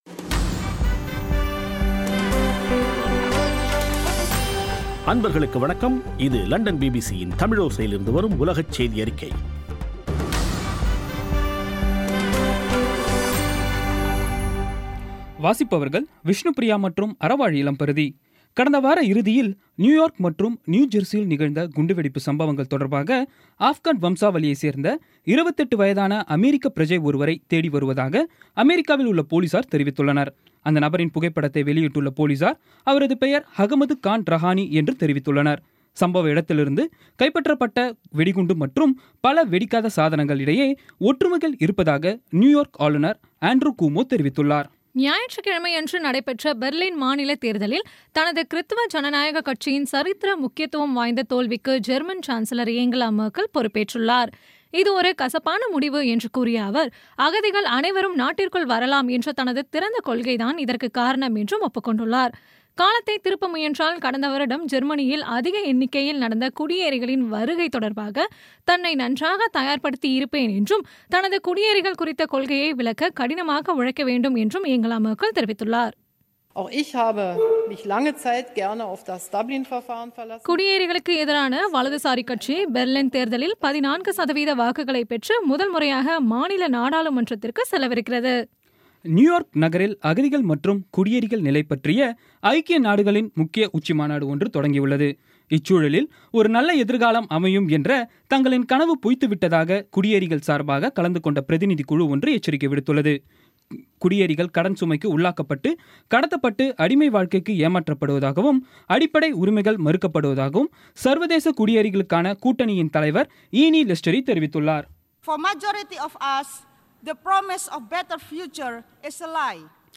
இன்றைய (ஆகஸ்ட் 19ம் தேதி ) பிபிசி தமிழோசை செய்தியறிக்கை